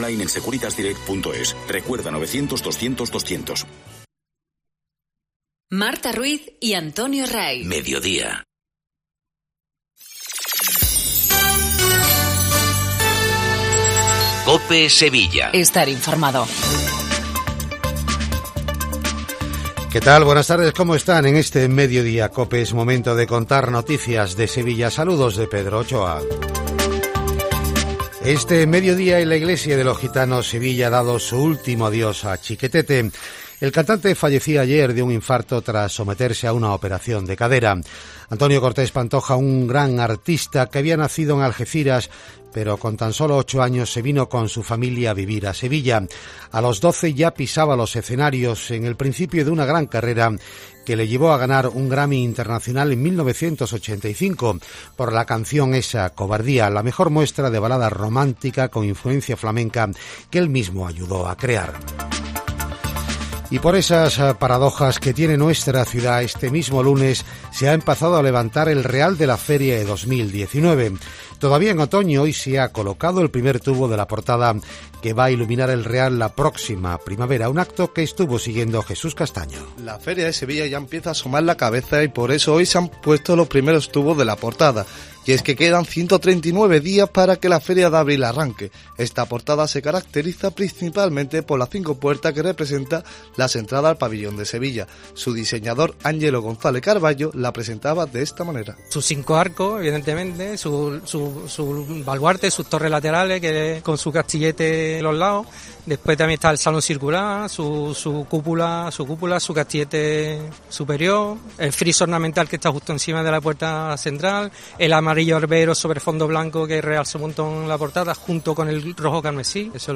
MEDIODIA EN COPE SEVILLA. INFORMATIVO 14:20